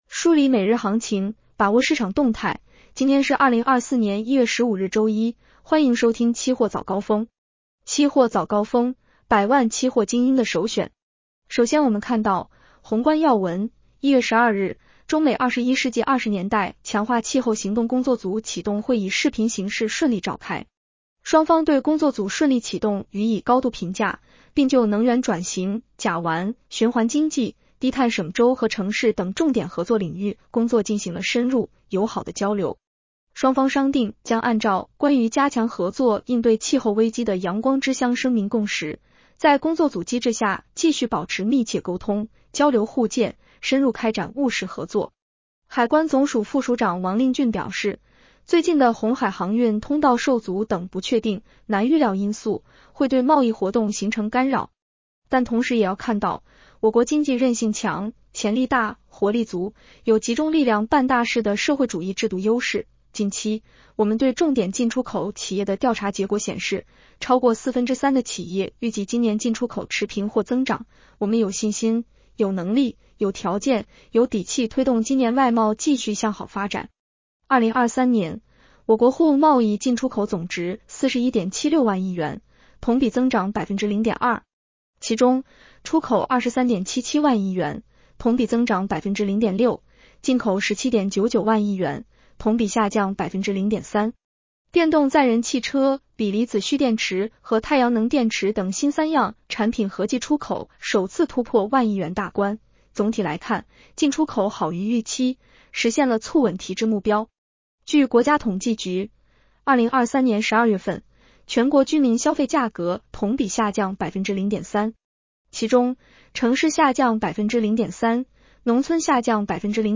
【期货早高峰-音频版】 女声普通话版 下载mp3 宏观要闻 1. 1月12日， 中美“21世纪20年代强化气候行动工作组”启动会以视频形式顺利召开。